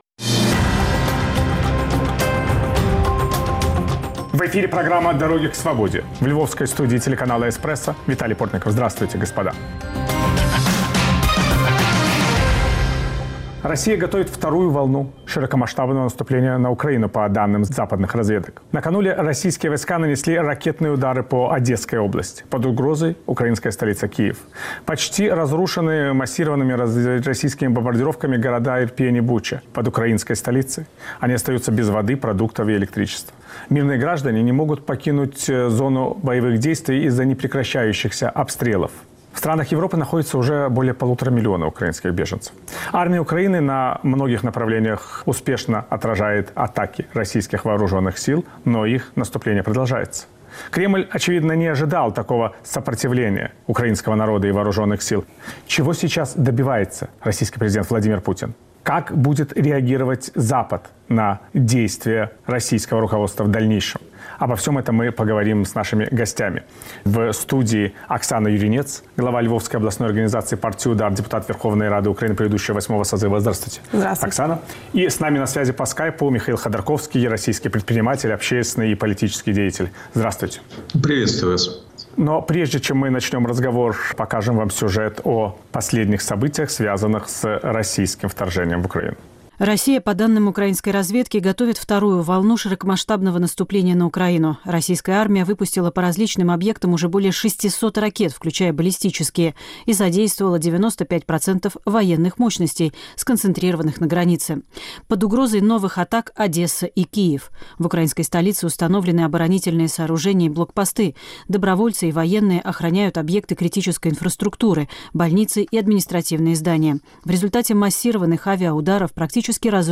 В студии Оксана Юринец, глава Львовской областной организации партии «УДАР» и Михаил Ходорковский, предприниматель, общественный и политический деятель.